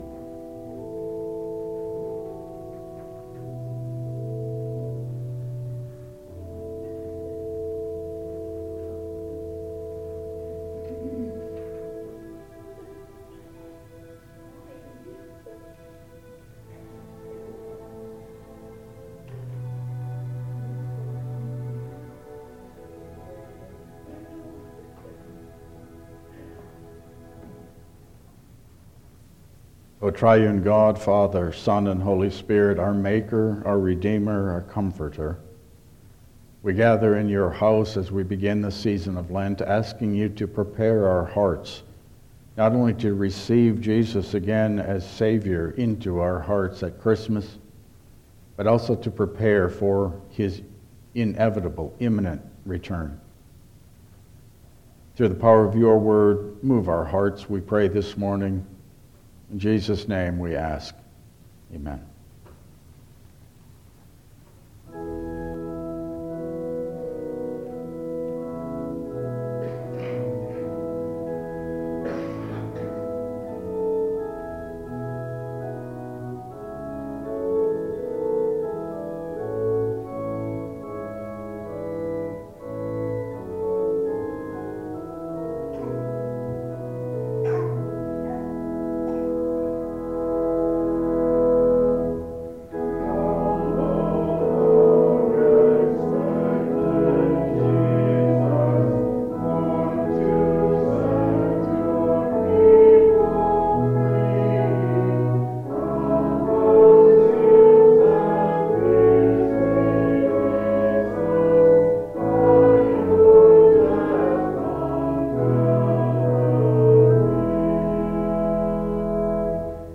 Passage: Romans 13:11-14 Service Type: Regular Service